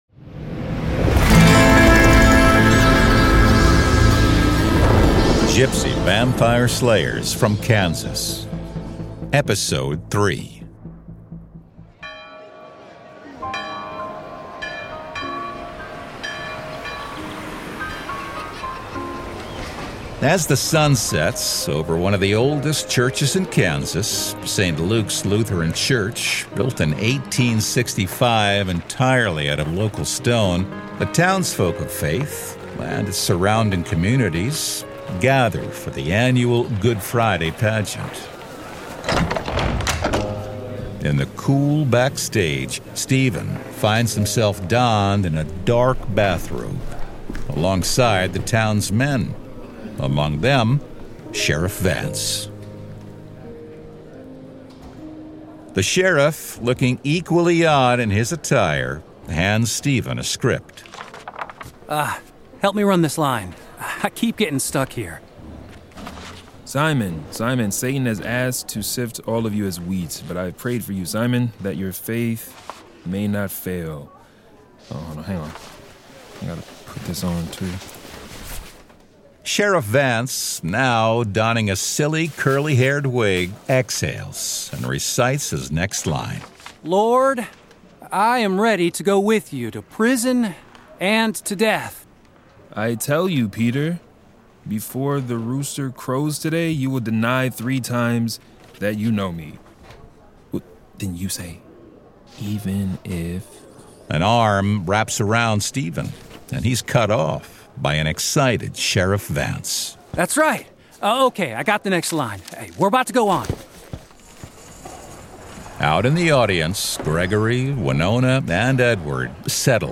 Format: Audio Drama
Voices: Full cast
Narrator: Third Person
Soundscape: Sound effects & music